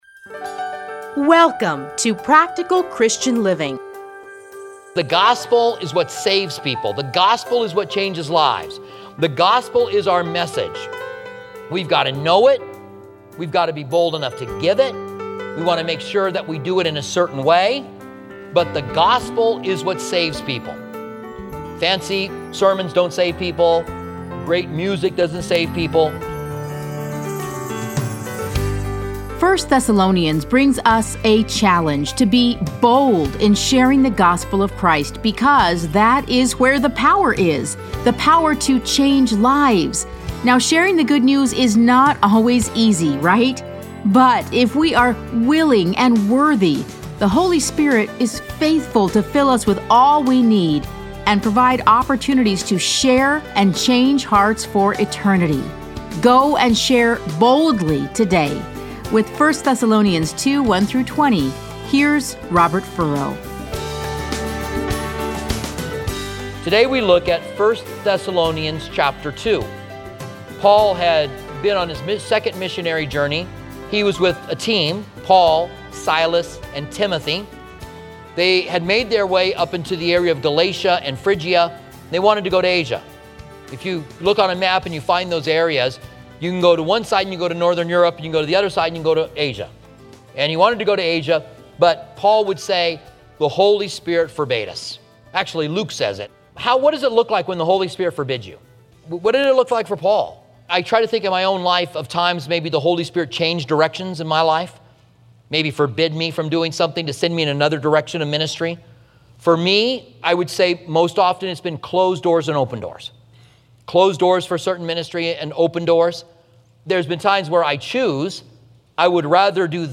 Listen to a teaching from 1 Thessalonians 2:1-20.